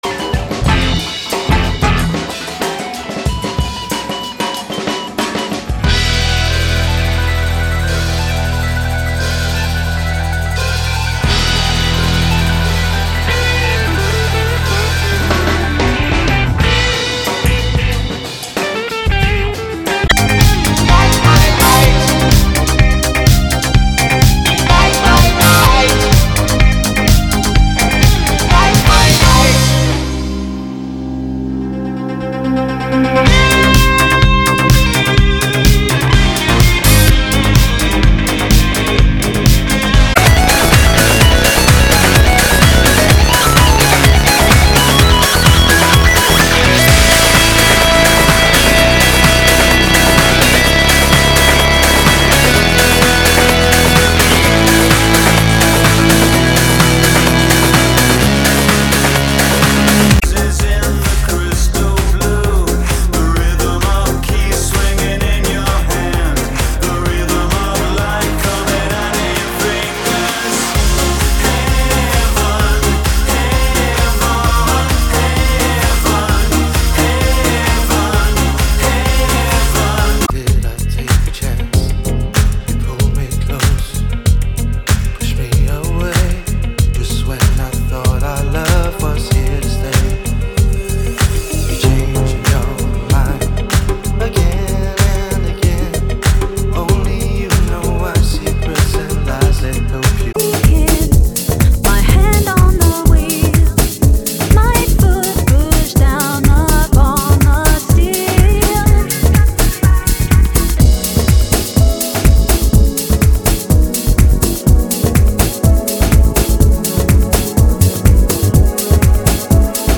MP3 sound bite (7 MB). 20 seconds from each song.